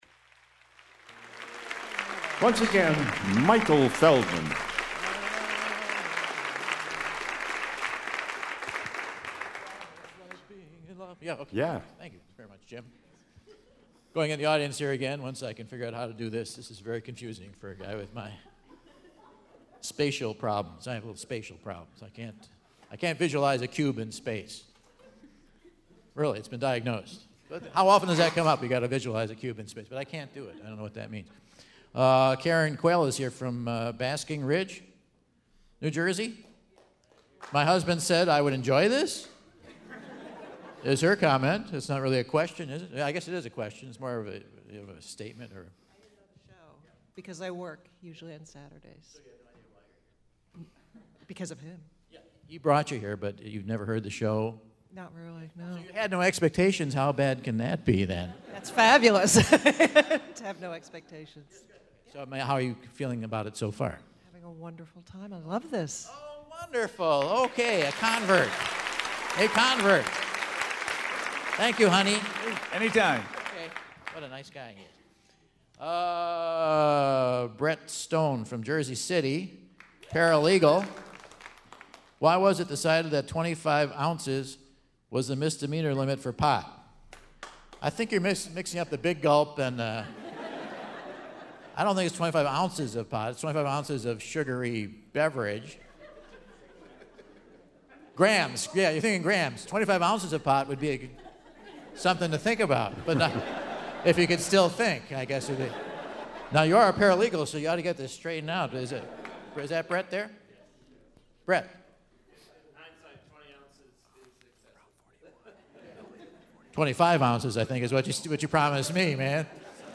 Two more contestants engage with the Whad'Ya Know? Quiz!